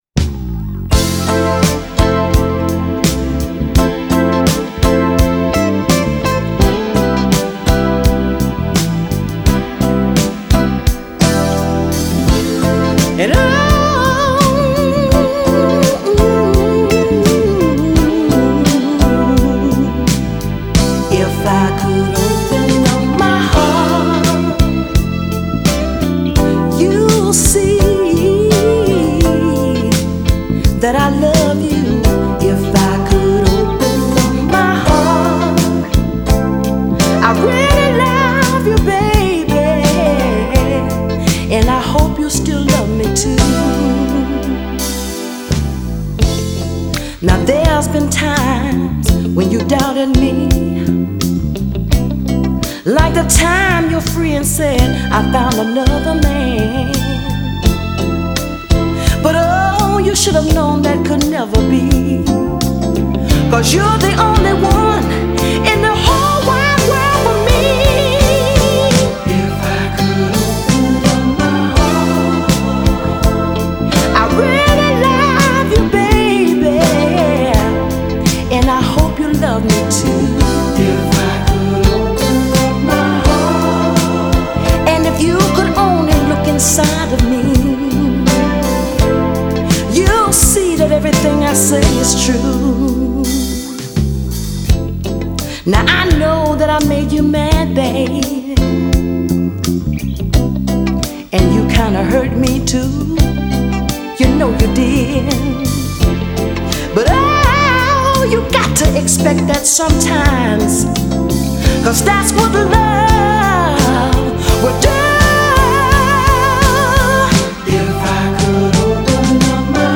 a gorgeous stepper
just oozes with soul